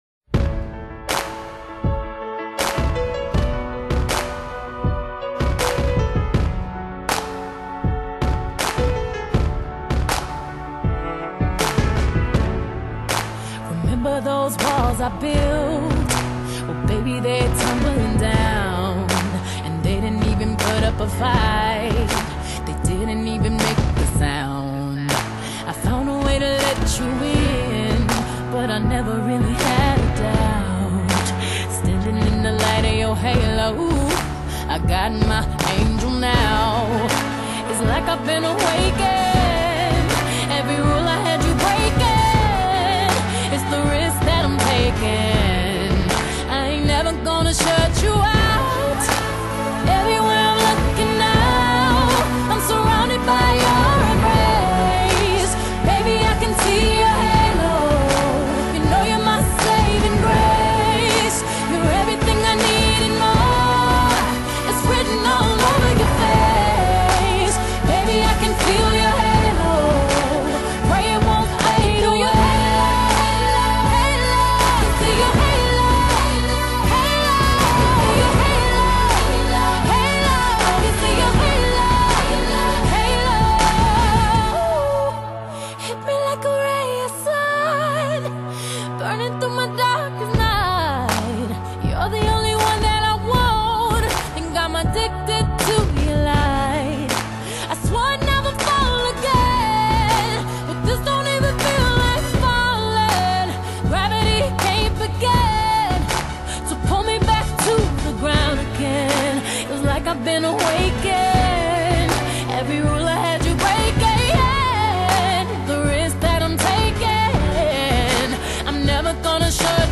風靡全球18國排行榜Top 10的動感酷歌